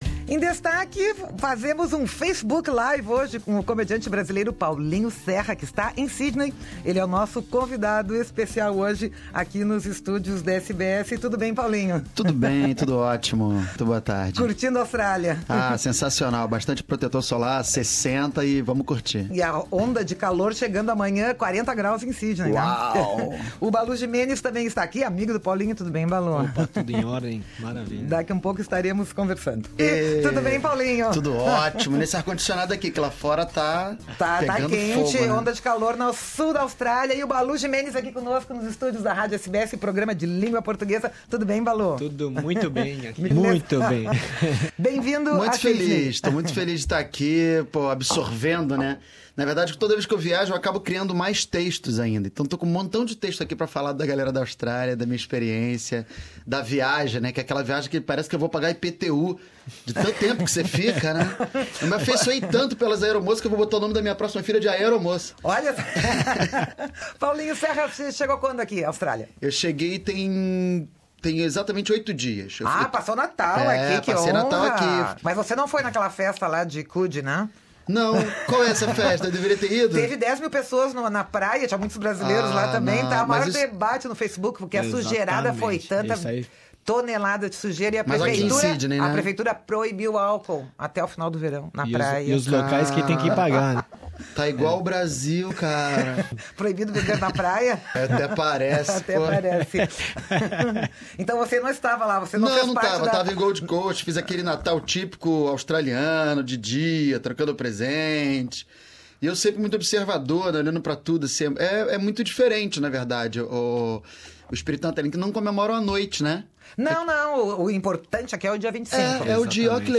O comediante brasileiro Paulinho Serra fala nesta entrevista da sua viagem à Austrália, do políticamente correto inibindo e policiando o humorista, da pouca tradição do "stand-up comedy" no Brasil, se é possível rir da política brasileira e conta dos seus planos daqui para a frente: YouTuber e Big Brother, entre muitos outros.